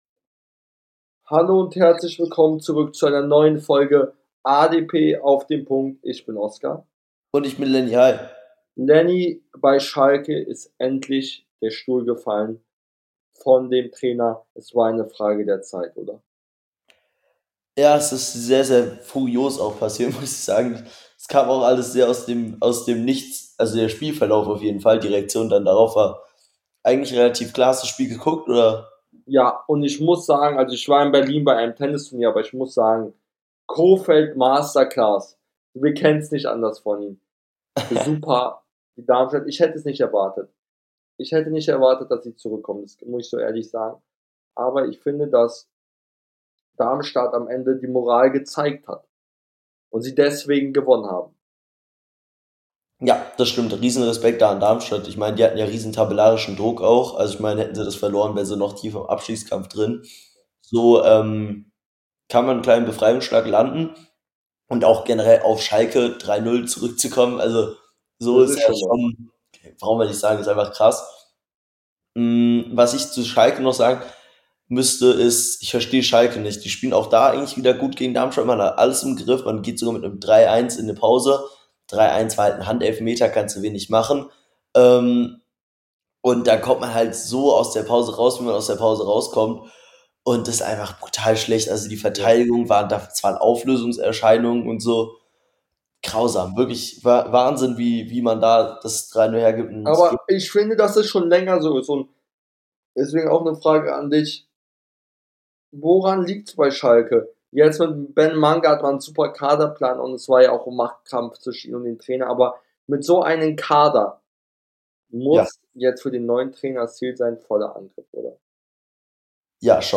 In der heutigen Folge reden die beiden Hosts über Schalkes bittere Niederlage gegen Darmstadt und den Trainerwechsel , Stuttgarts Abreibung gegen Dortmunds , Bremens Klatsche und vieles mehr